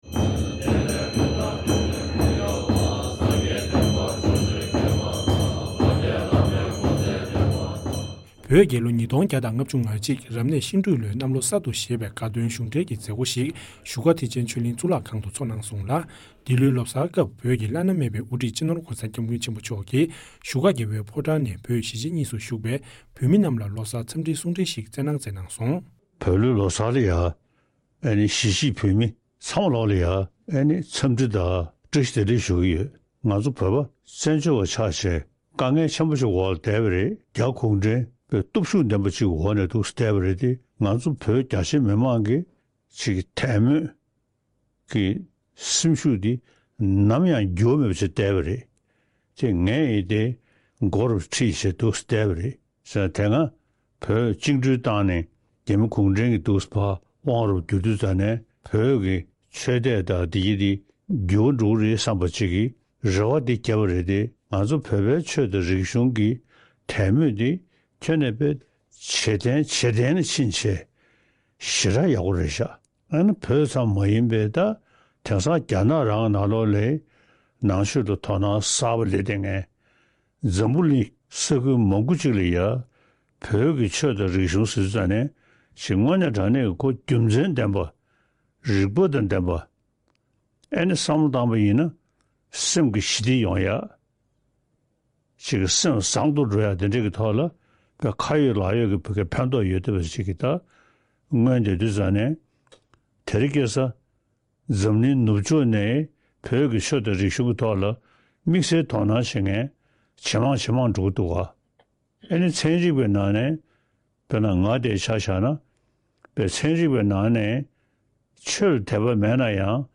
བོད་ཀྱི་ལོ་གསར་གཞུང་འབྲེལ་མཛད་སྒོ་བཞུགས་སྒར་ཐེག་ཆེན་ཆོས་གླིང་གཙུག་ཁང་དུ་འཚོཊ་གནང་བ།